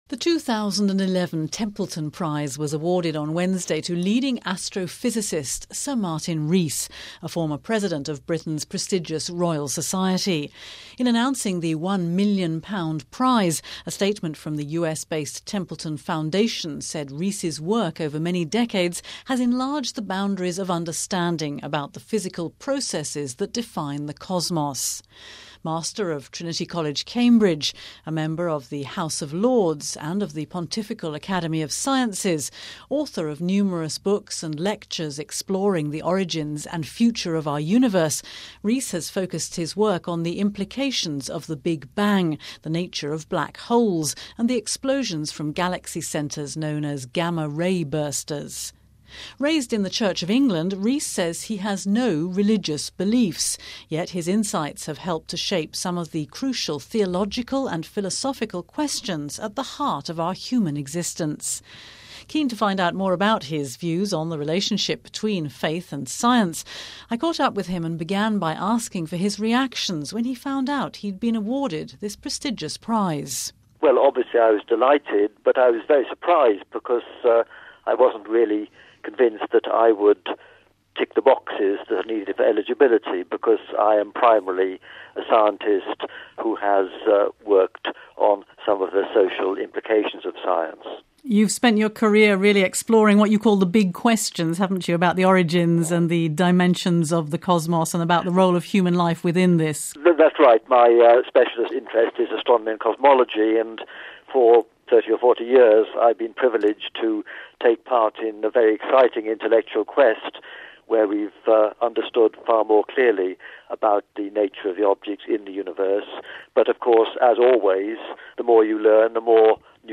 spoke to him to find out more about his work and his views on the relationship between faith and science…